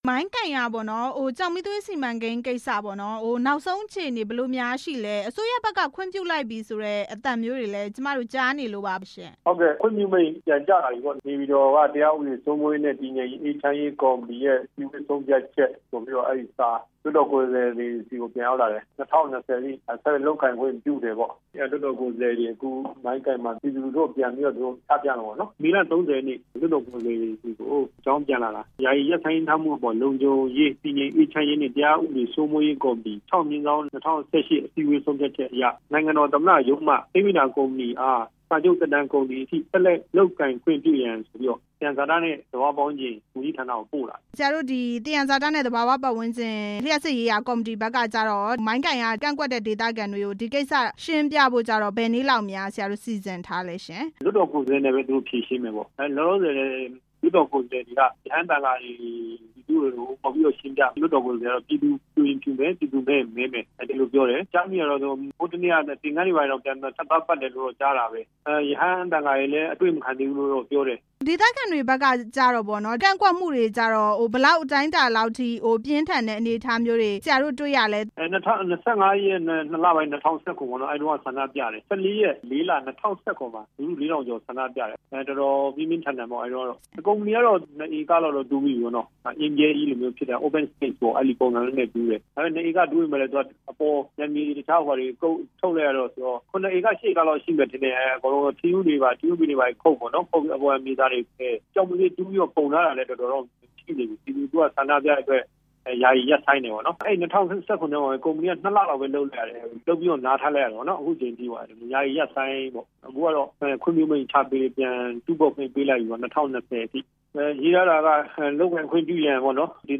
မိုင်းကိုင် ကျောက်မီးသွေးစီမံကိန်းအကြောင်း မေးမြန်းချက်